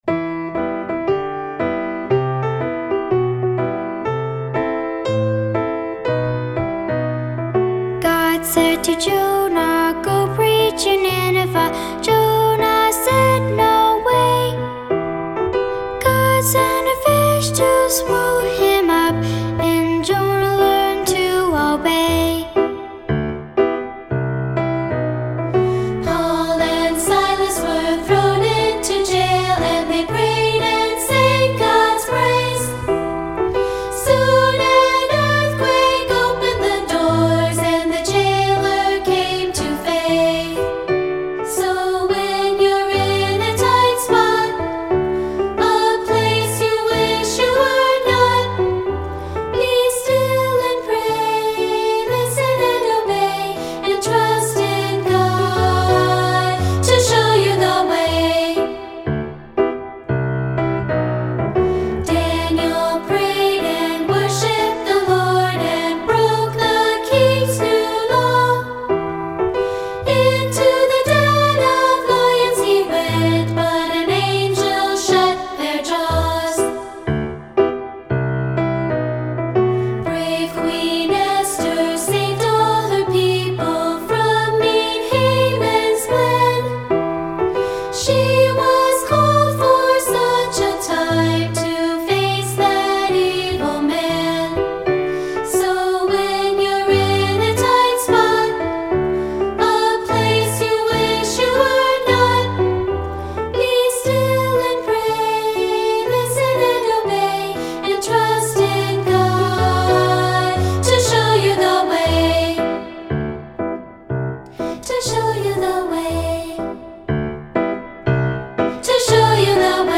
Voicing: Unison and Piano